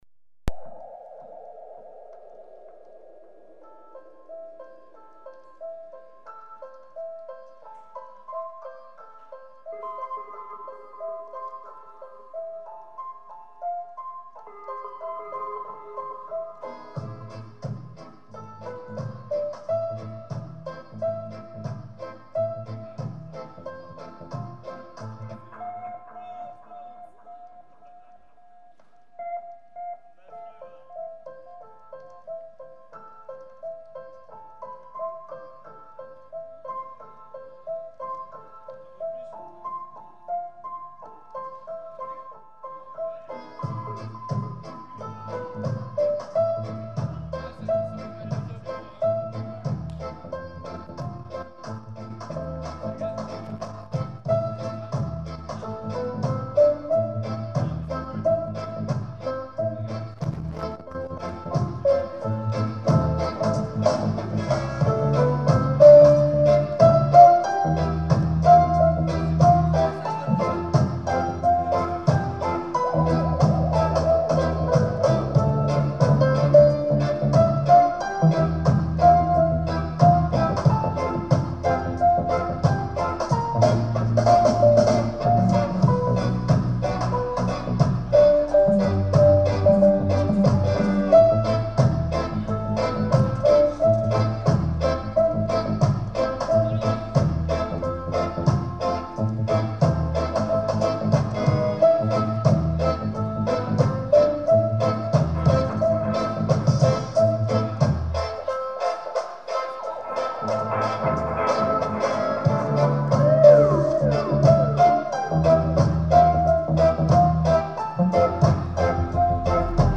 session